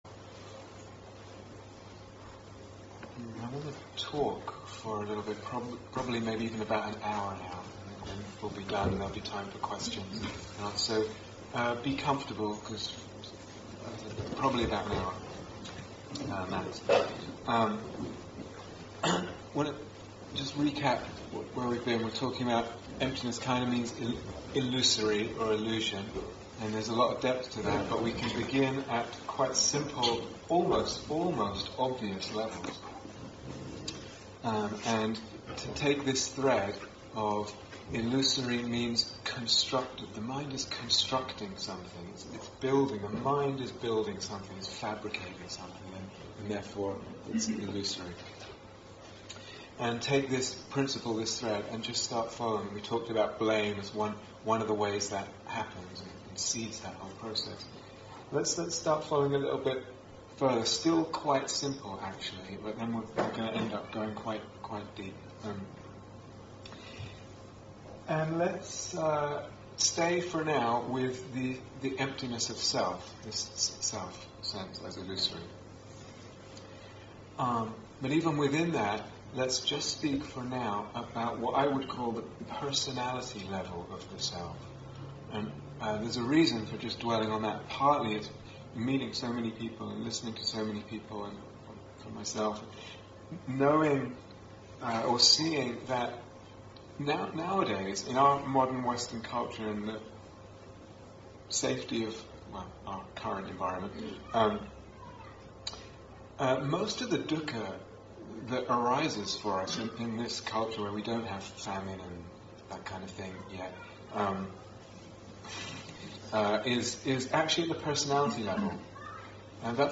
Retreat/Series Day Retreat, London Insight 2012